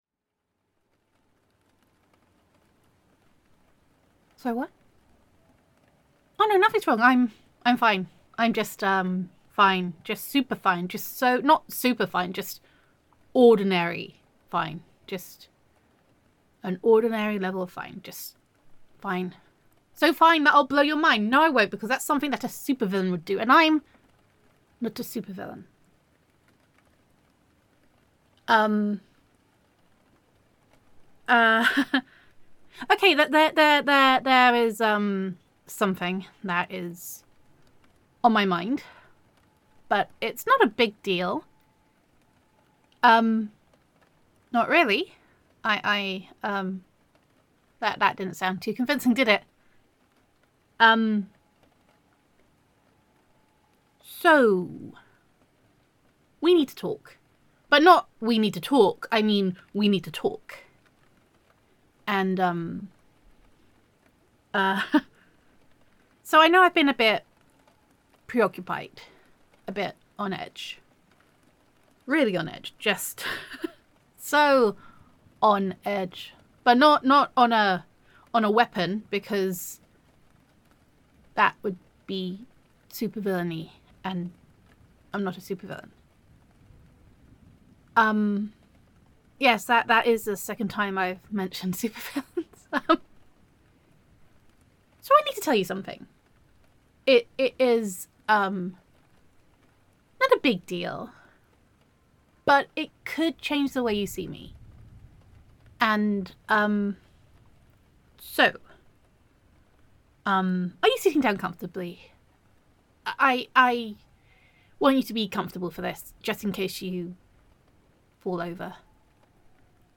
[F4A] I Need to Tell You Something [Adorable][Cute][Girlfriends Roleplay][Doctor Chaos][Superhero Listener][Gender Neutral][Your Girlfriend Confesses That Her Father Is a Supervillain]